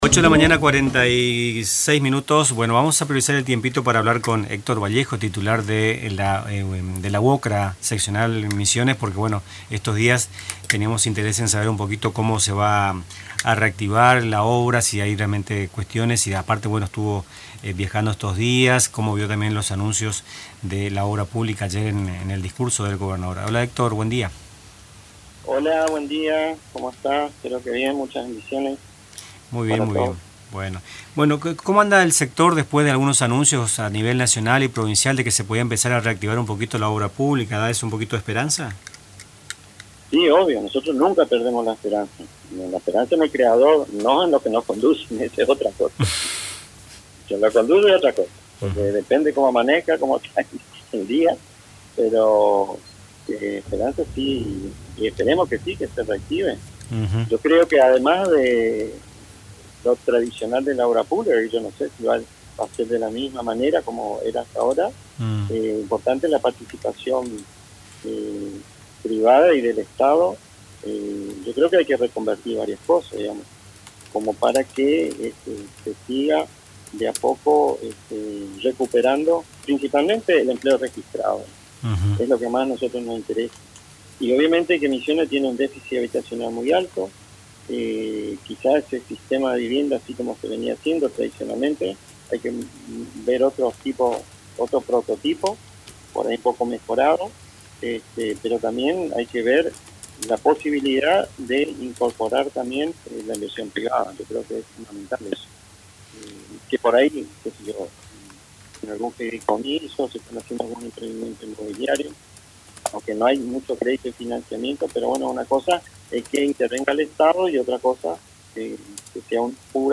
En una reciente entrevista con Radio Tupa Mbae